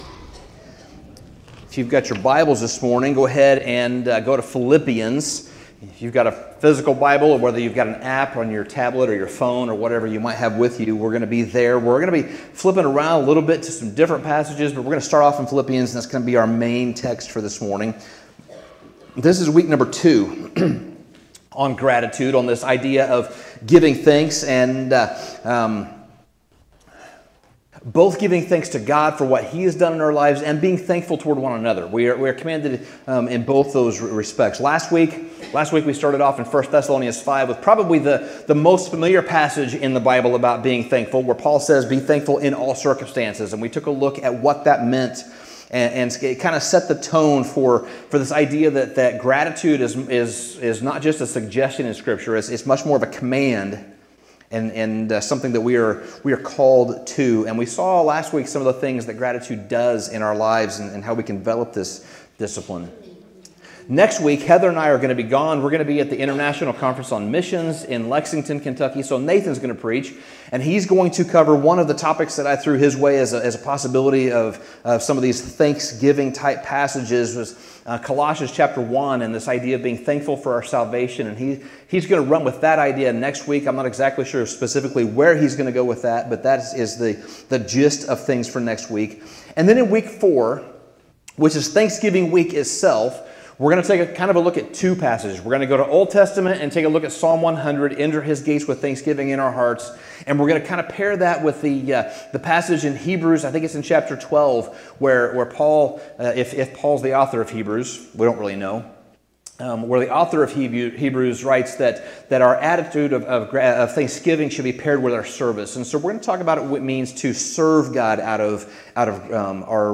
Sermon Summary There is a transformative power to gratitude which can be seen clearly in Paul's encouragement to the Philippian church in Philippians 4:6-7. He encourages believers to replace anxiety with thanksgiving in their prayers, emphasizing that through gratitude, we open our hearts to God’s peace.